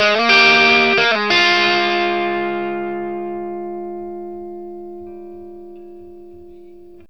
BLUESY1 BF60.wav